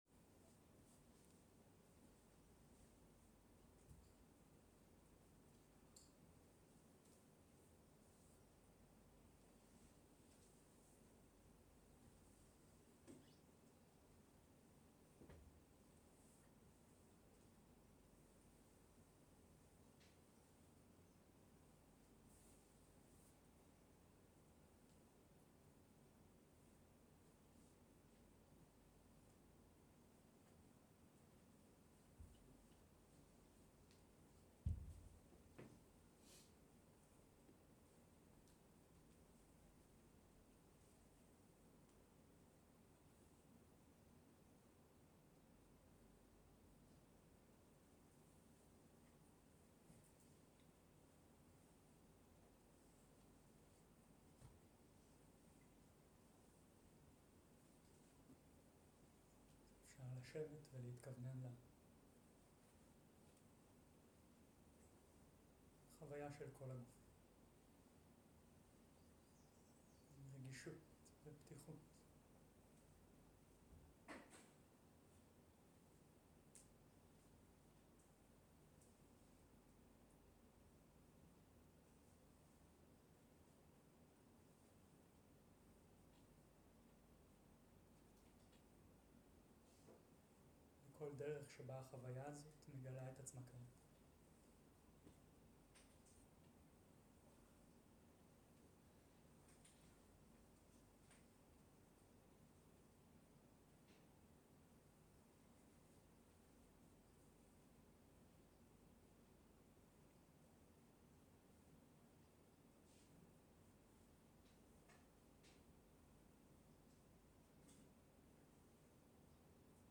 הנחיות בוקר מדיטציה